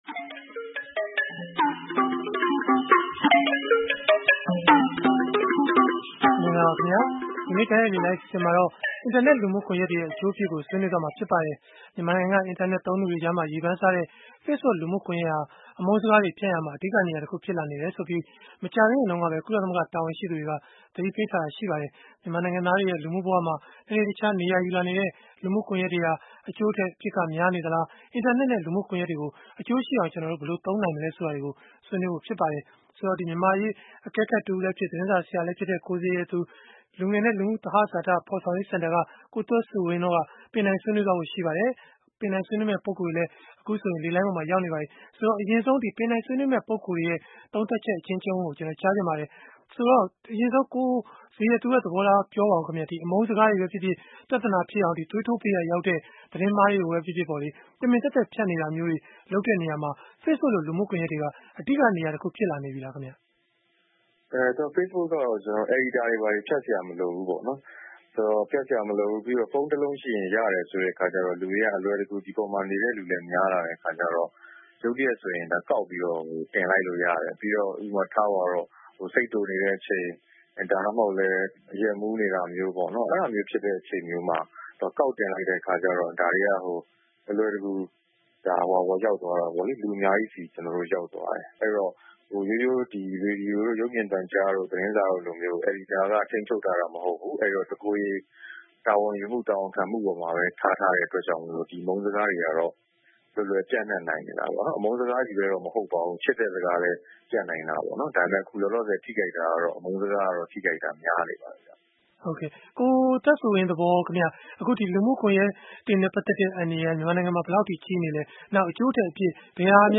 ဗွီအိုအေရဲ့ စနေနေ့ည တိုက်ရိုက်လေလှိုင်း အစီအစဉ်မှာ